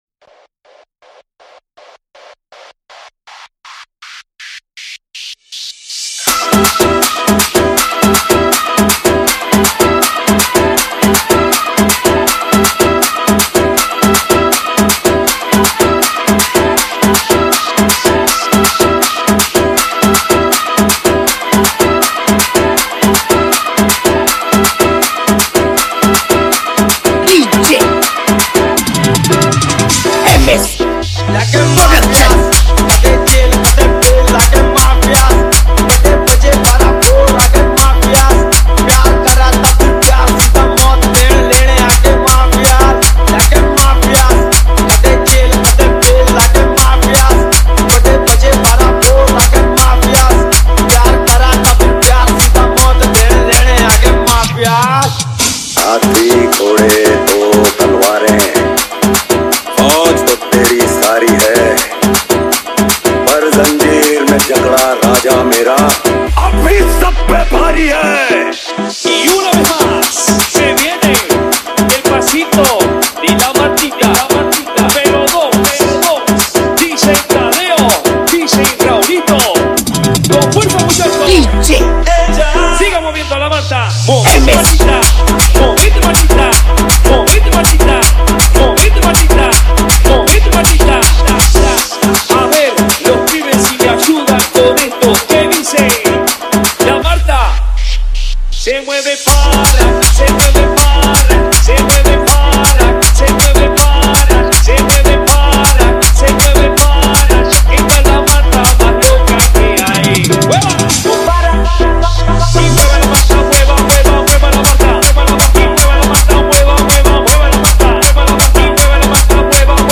Category : Mashup Remix Song